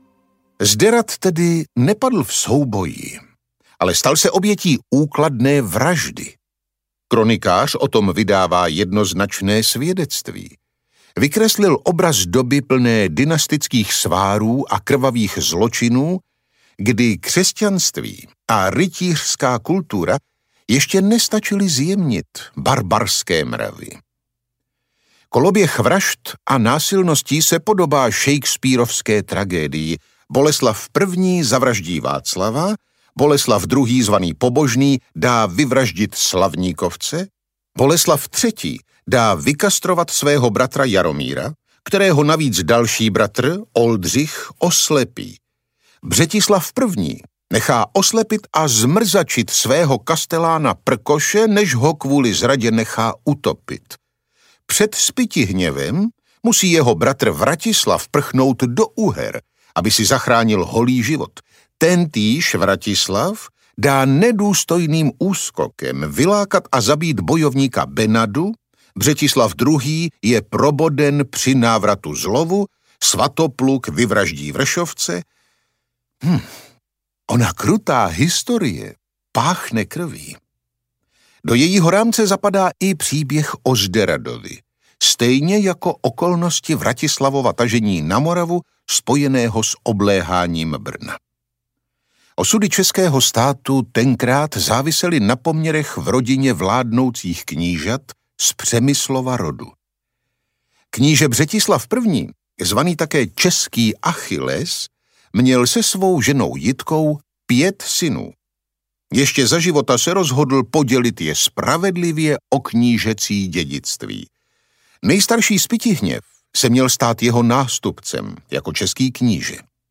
Audiokniha Krvavý úterek - Dušan Uhlíř | ProgresGuru